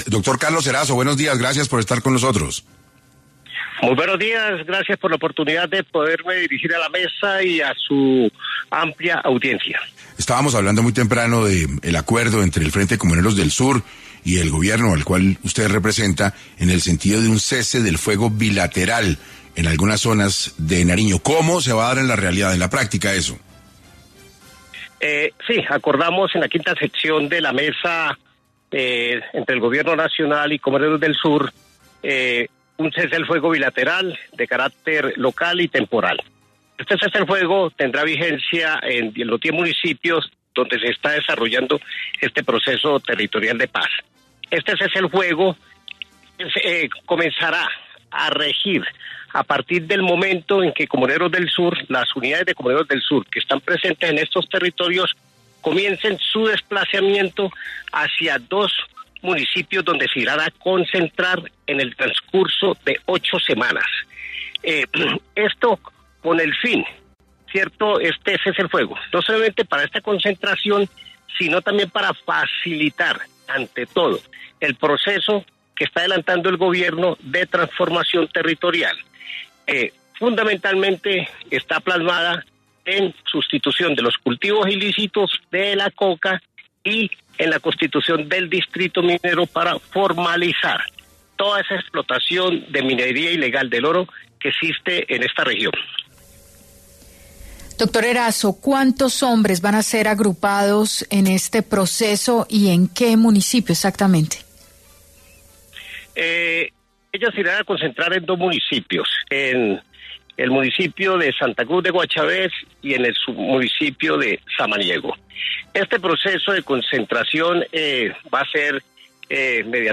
En 6AM de Caracol Radio estuvo Carlos Erazo, jefe de la delegación del Gobierno con el grupo armado “Comuneros del Sur”, para hablar sobre cómo llevarán a cabo el cese al fuego.